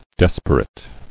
[des·per·ate]
Des"per*ate*ness n. Desperation; virulence.